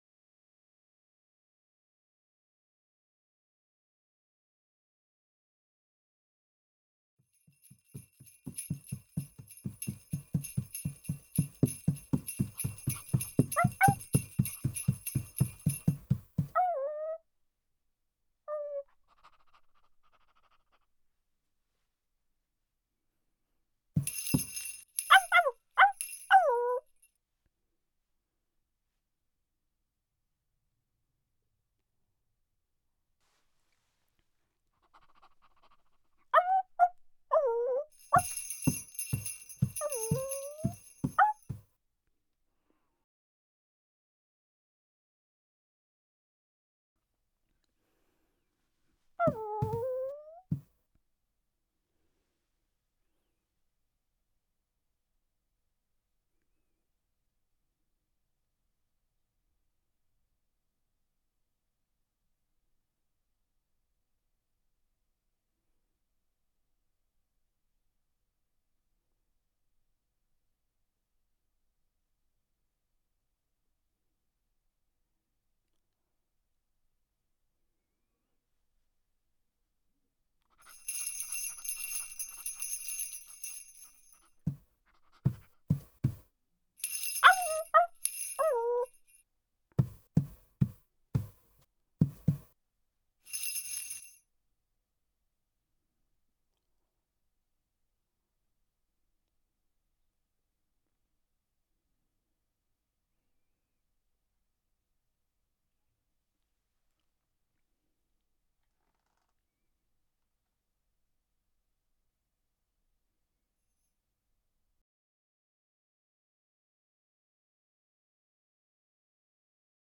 SFX_Scene03_Dog 1.ogg